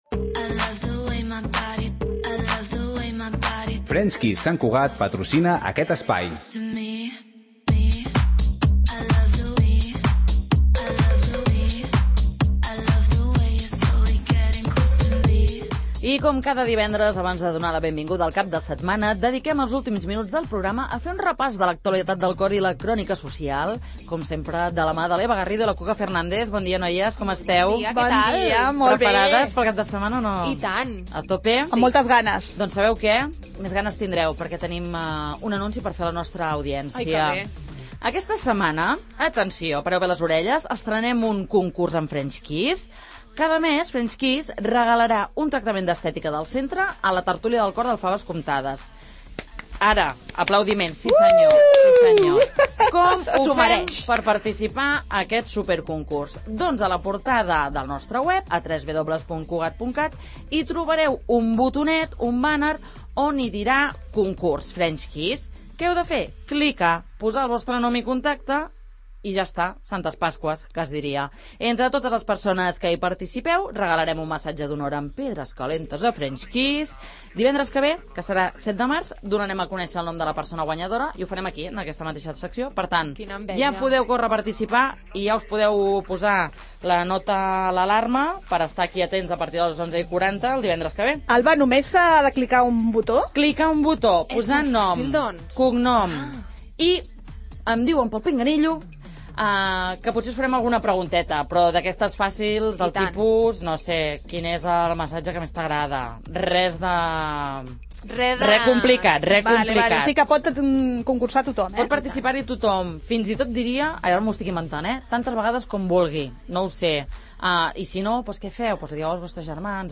Les 'il�lusions' de Lola Lolita, Sara Carbonero i Laura Escanes, a la tert�lia del cor de R�dio Sant Cugat